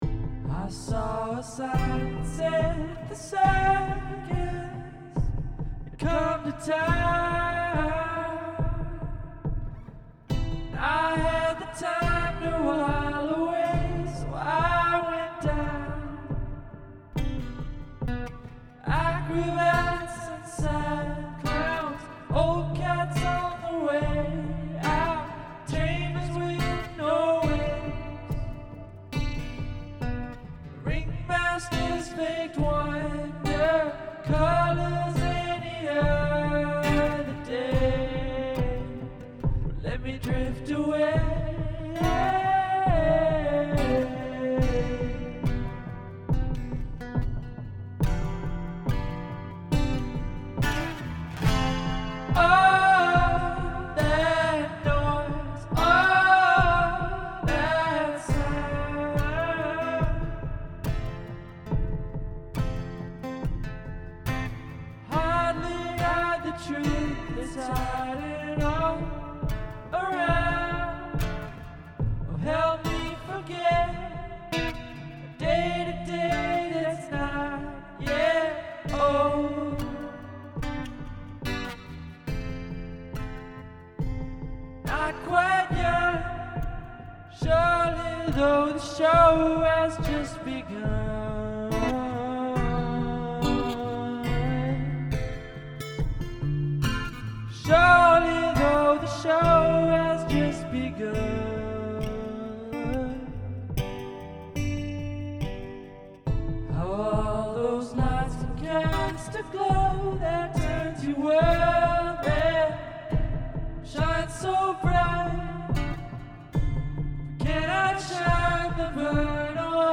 Use something from your garbage bin as an instrument